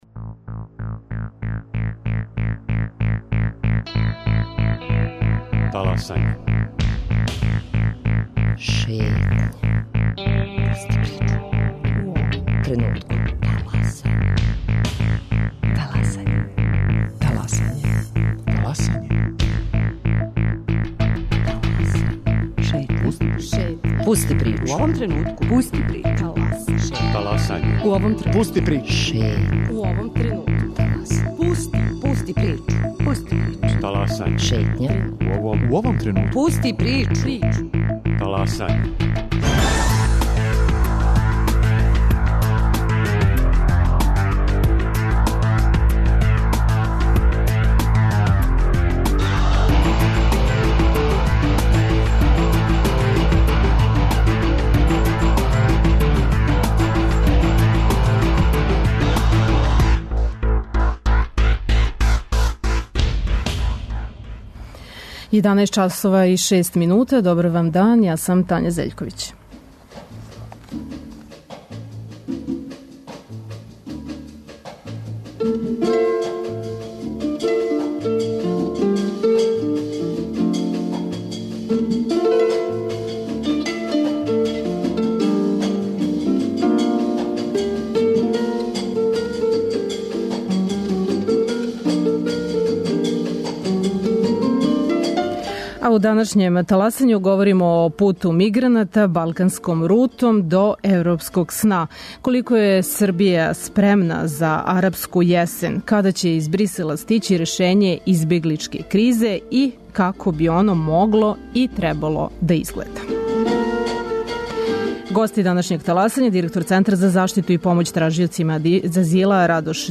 Гости